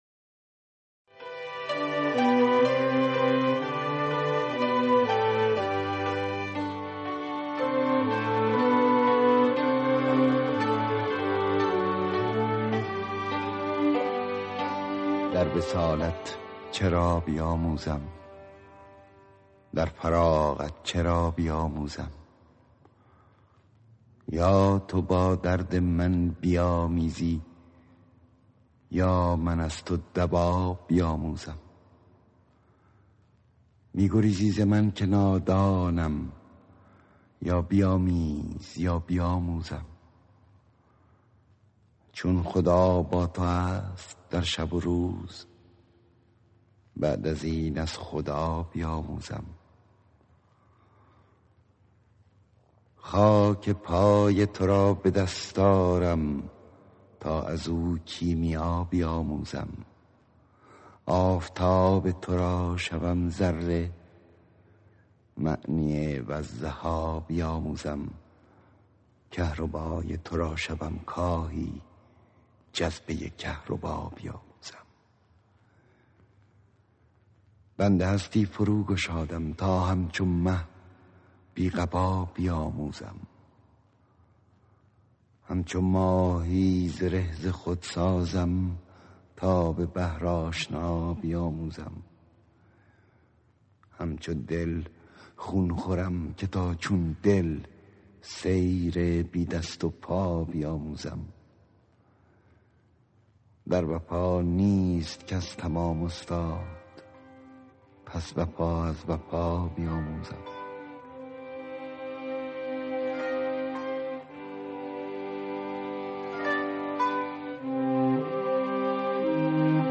صدای: احمد شاملو
موسیقی متن:‌ فریدون شهبازیان
شعر خوانی احمد شاملو
شعرهای-مولوی-با-صدای-احمد-شاملو-راوی-حکایت-باقی-8.mp3